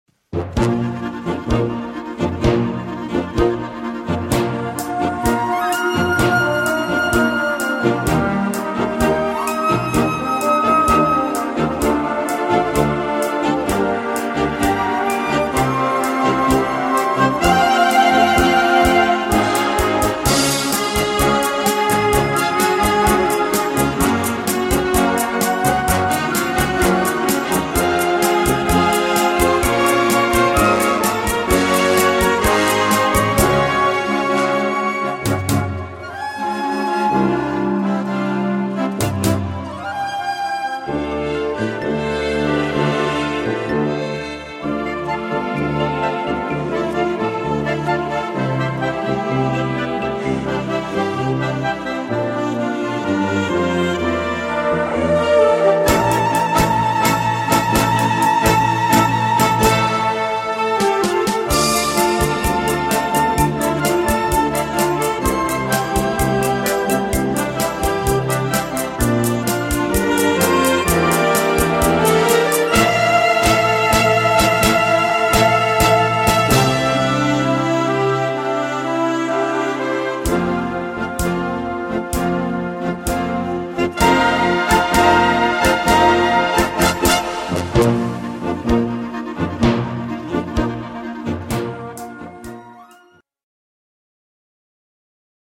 Filmmusik Schwierigkeit
Blasorchester